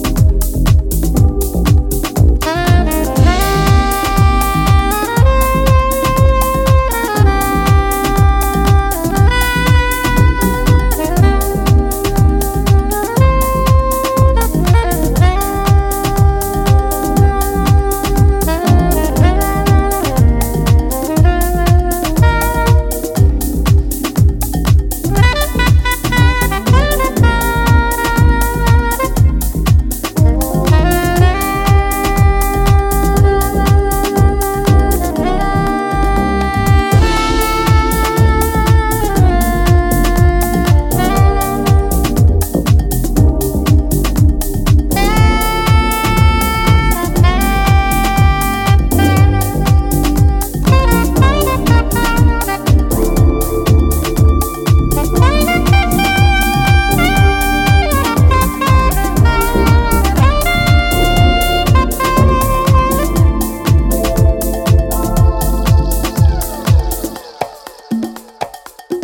なんとも抗し難い、温かく沁み込む最高のインスト・ハウスに仕上がっています。